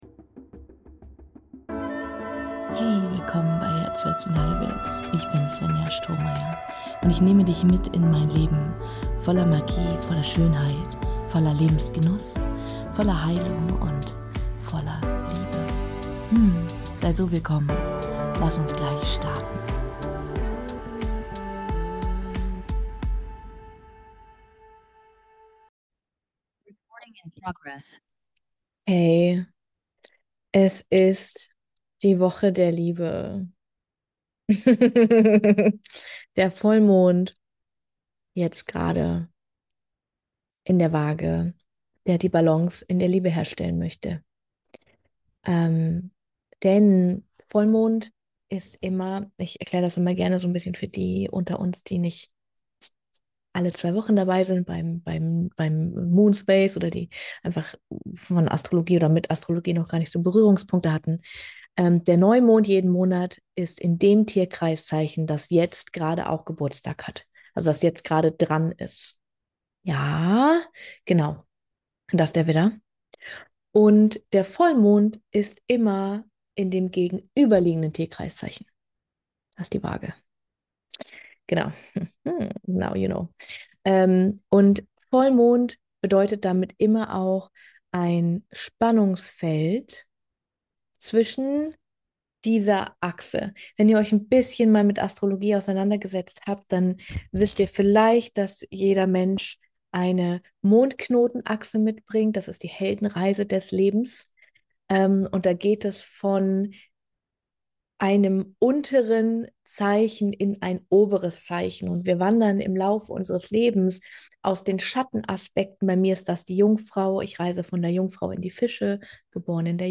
Diese Folge ist aus einem meiner Vollmond-Räume der Rosenlinie entstanden – genauer gesagt aus der Energie des Vollmonds in der Waage, der uns einlädt, Balance in unserer Liebe, unseren Beziehungen und unseren Werten zu finden. Es geht um die Frage, wie wir wieder in Einklang mit uns selbst kommen und Klarheit darüber gewinnen, was uns wirklich entspricht. Was dich hier erwartet, ist eine verkörperte Erfahrung – eine geführte Reise aus Atem, Wahrnehmung und innerer Ausrichtung.